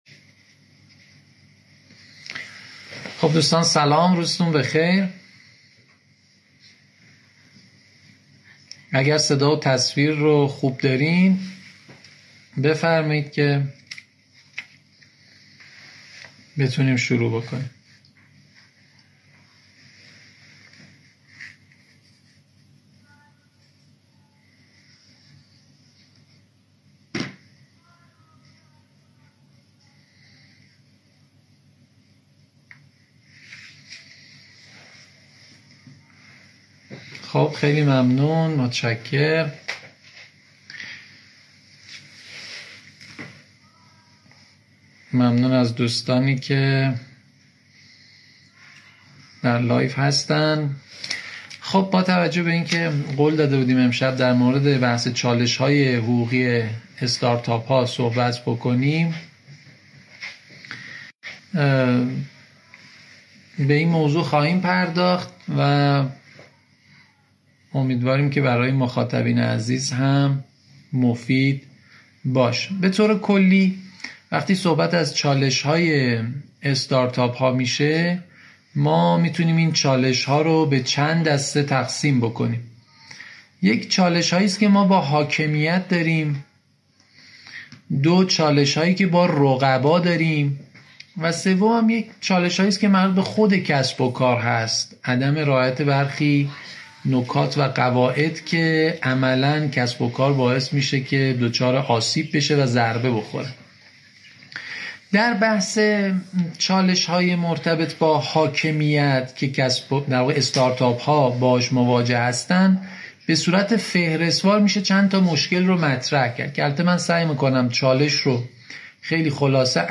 در این گفتگوی تحلیلی و عمیق